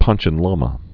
(pänchən lämə)